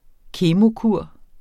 Udtale [ ˈkeːmo- ]